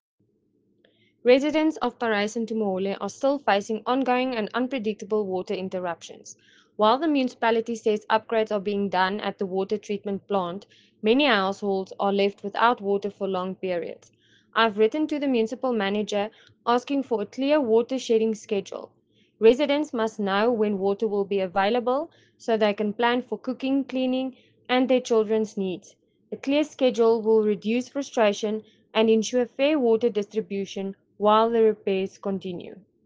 Afrikaans soundbites by Cllr Marié la Cock and